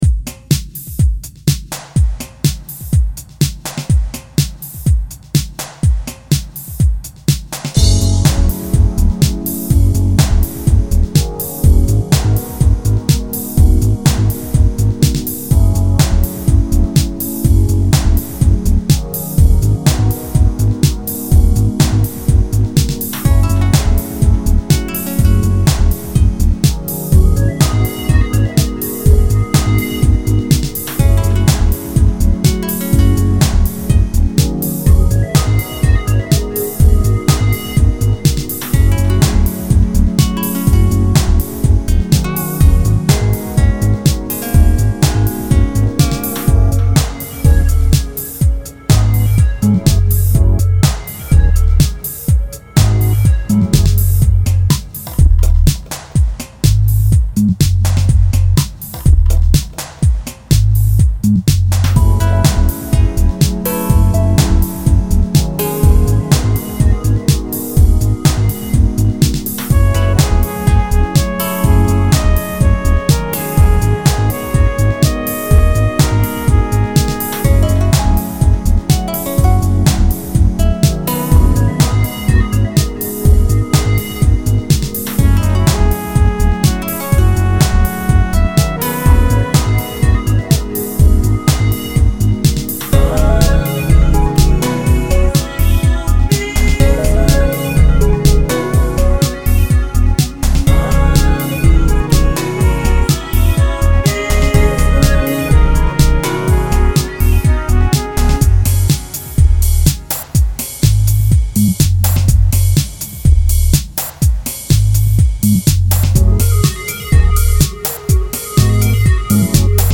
Chillout